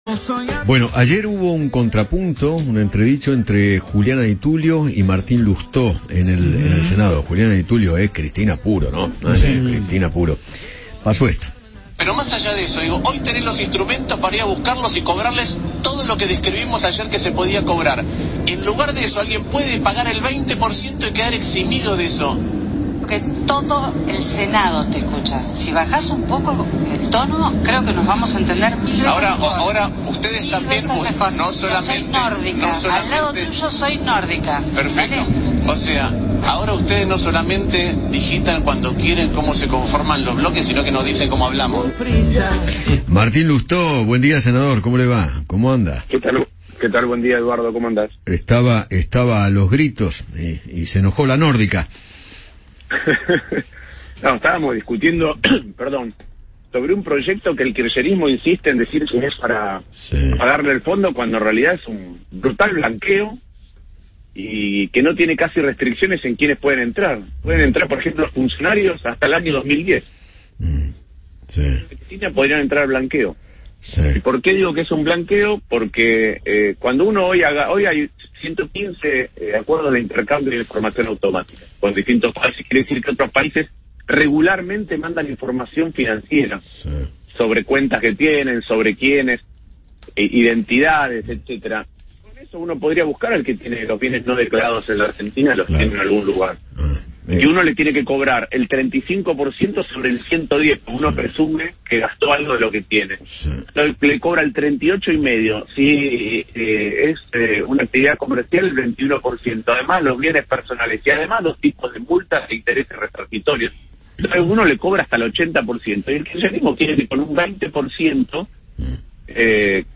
Martín Lousteau, senador nacional de Juntos por el Cambio, conversó con Eduardo Feinmann sobre el cruce que mantuvo con la senadora Juliana Di Tullio en la Cámara de Diputados, cuando debatían por el proyecto oficialista para crear un impuesto a bienes no declarados.